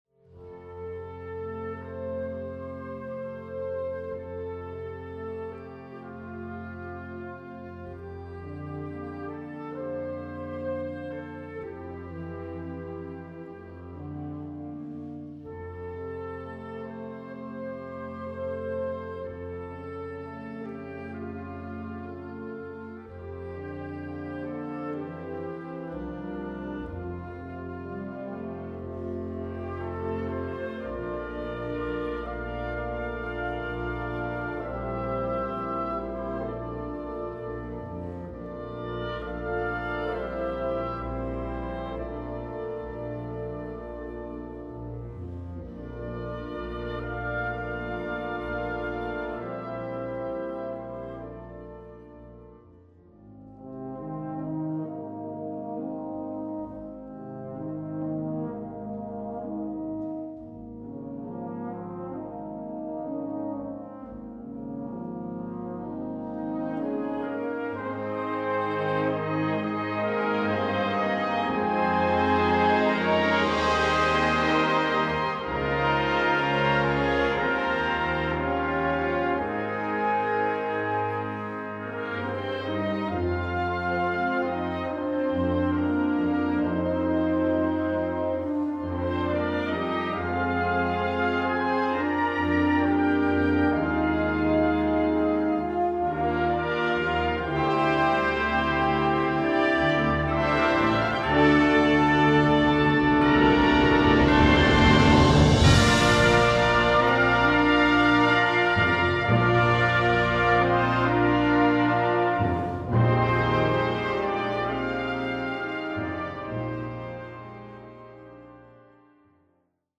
Gattung: Symphonic Chorale based on Folk Music
Besetzung: Blasorchester